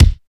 28 KICK.wav